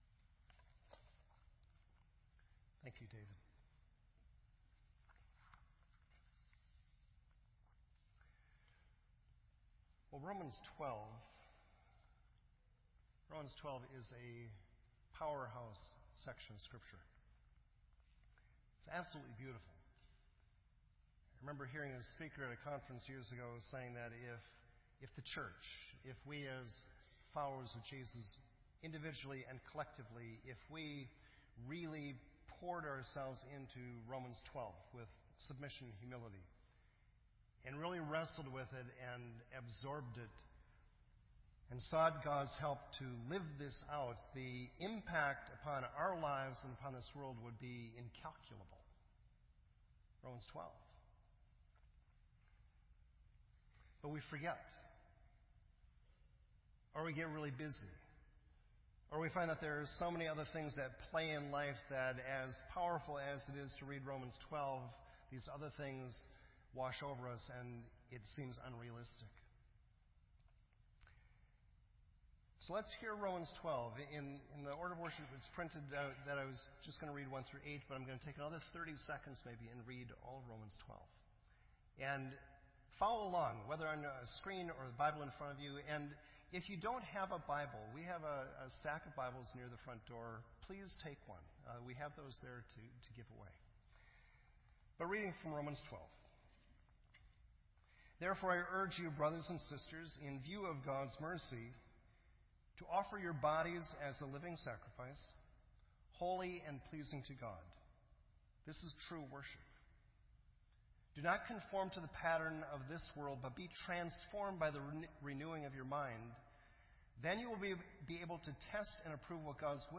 This entry was posted in Sermon Audio on September 4